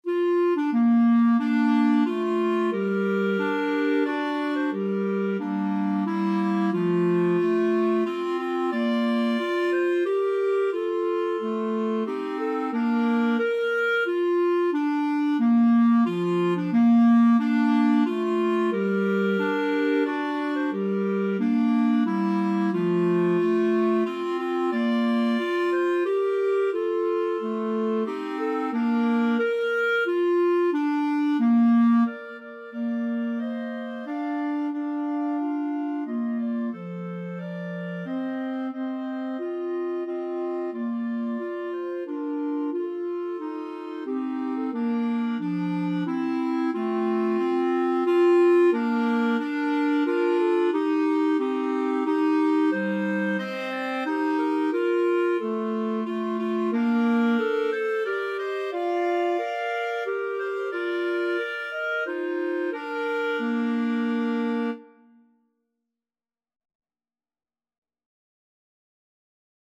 Bb major (Sounding Pitch) C major (Clarinet in Bb) (View more Bb major Music for Clarinet Trio )
Maestoso = c.90
3/4 (View more 3/4 Music)
Clarinet Trio  (View more Intermediate Clarinet Trio Music)
Traditional (View more Traditional Clarinet Trio Music)